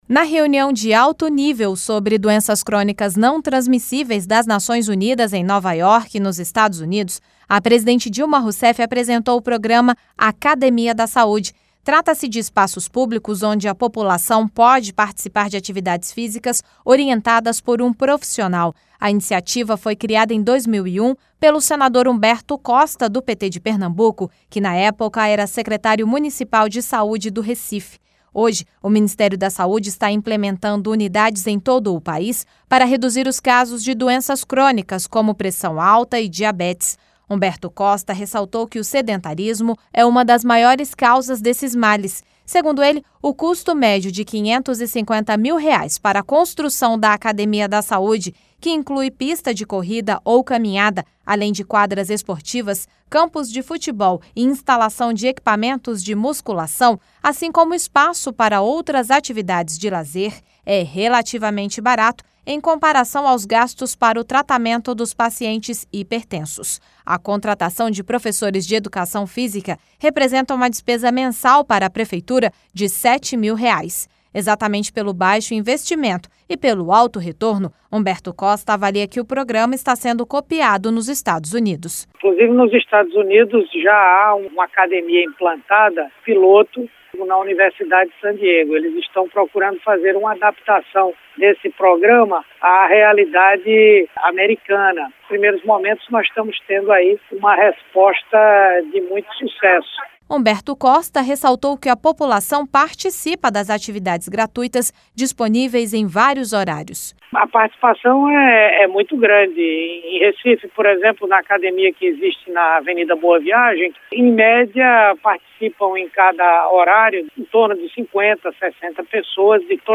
Rádio Senado